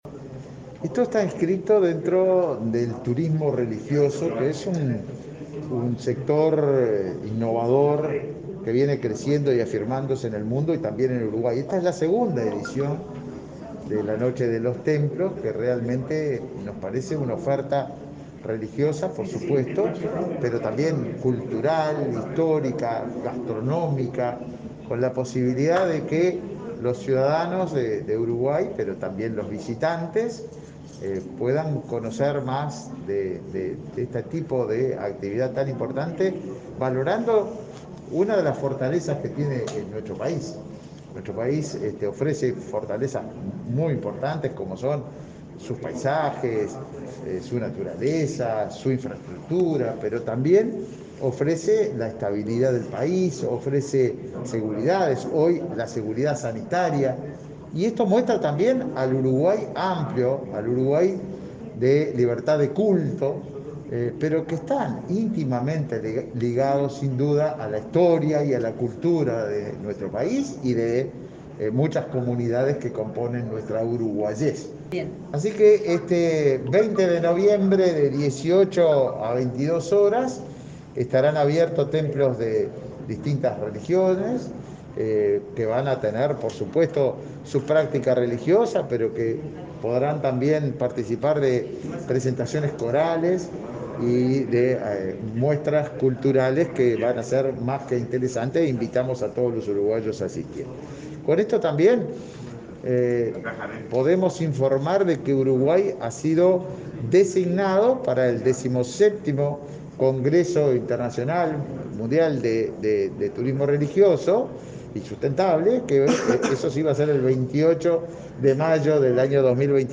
Declaraciones a la prensa del ministro de Turismo, Tabaré Viera